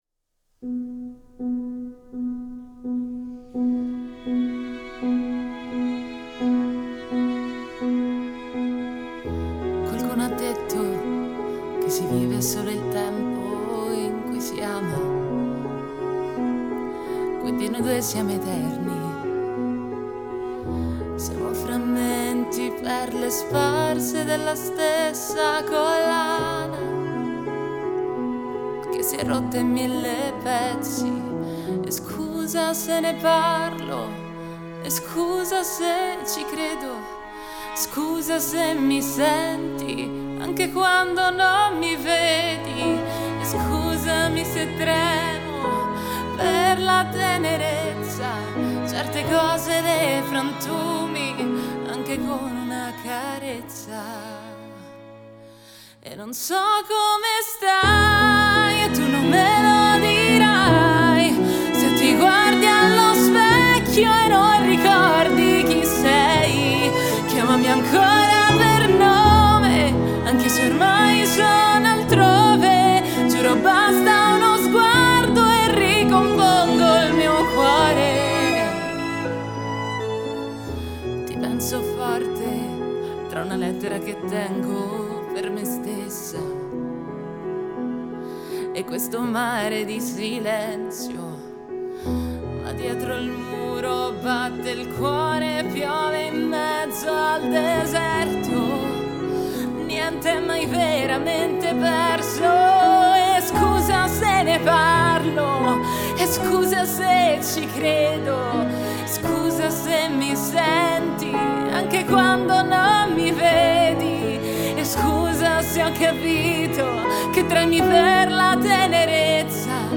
un’inedita performance dal vivo realizzata in presa diretta.
pianoforte, violino e violoncello
sospesa tra dolcezza e ruvidità
Girato a Roma